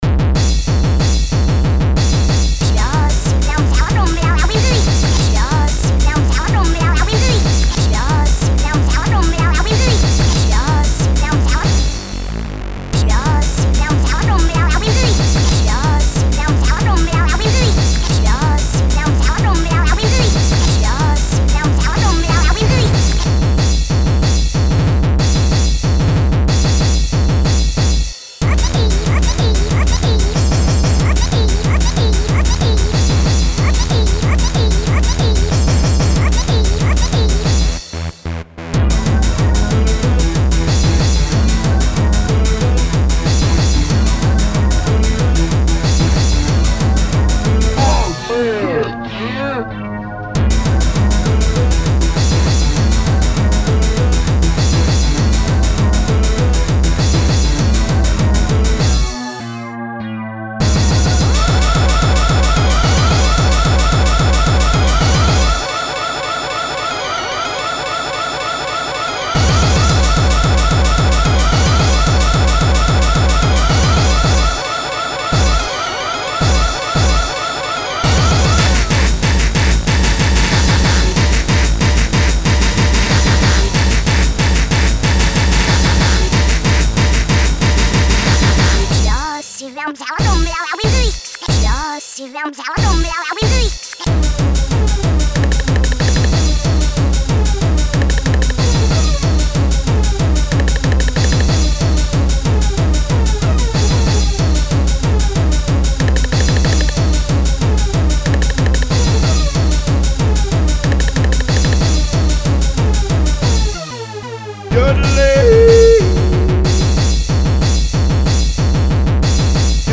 There are absolutely some wrong things in the song.